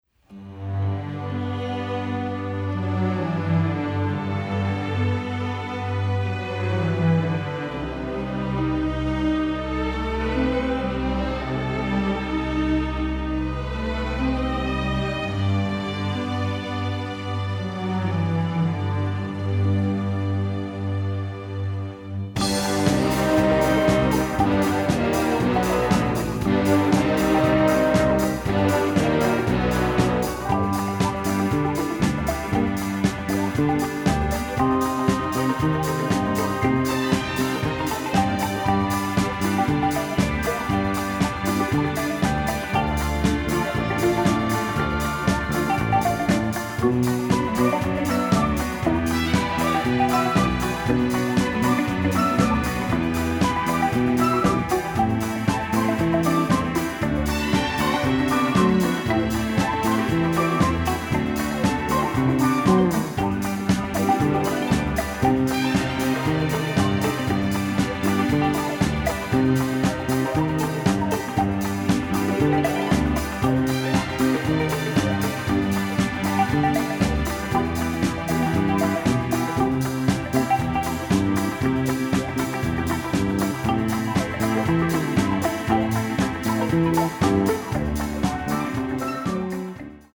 Alternate Instrumental
thrilling, often romantic score